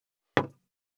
192,ポン,ガシャン,ドスン,ストン,カチ,タン,バタン,スッ,サッ,コン,ペタ,パタ,チョン,コス,カラン,ドン,チャリン,効果音,環境音,BGM,
コップ効果音物を置く
コップ